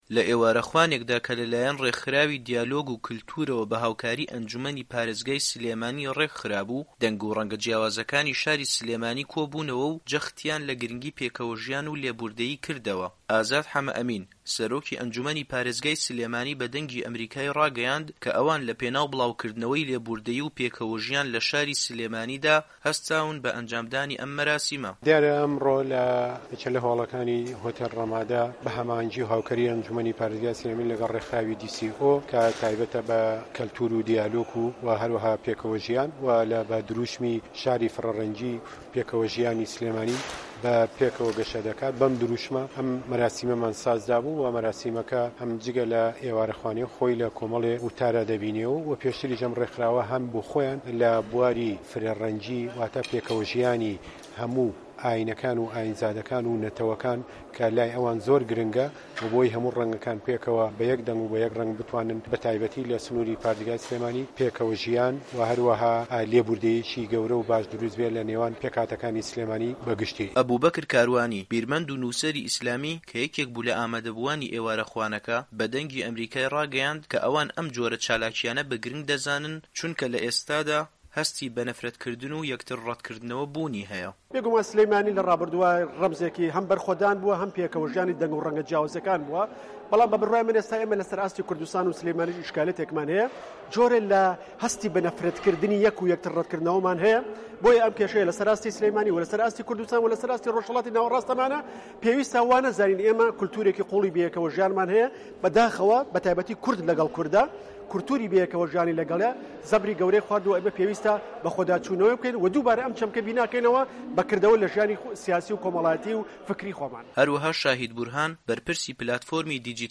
ئازاد حەمە ئەمین، سەرۆکی ئەنجوومەنی پارێزگای سلێمانی بە دەنگی ئەمەریکای ڕاگەیاند کە ئەوان لەپێناو بڵاوکردنەوەی لێبووردەیی و پێکەوەژیان لە شاری سلێمانیدا، هەستاون بە ئەنجامدانی ئەم مەراسیمە.
ڕاپـۆرتی